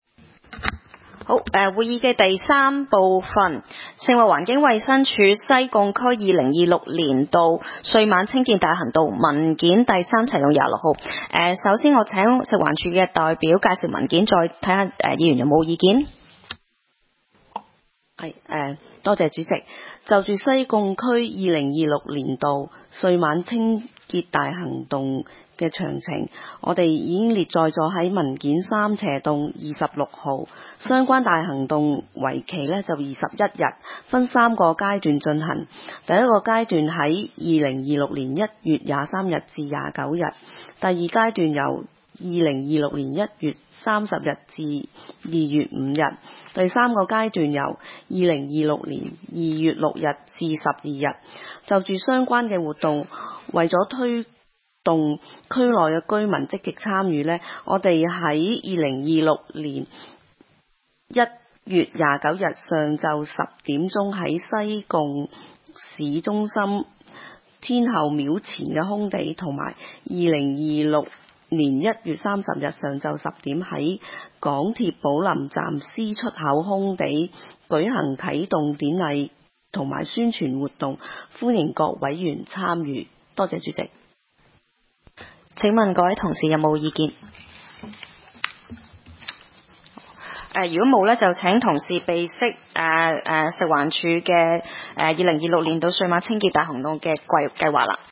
區議會大會的錄音記錄
西貢區議會第一次會議
西貢將軍澳政府綜合大樓三樓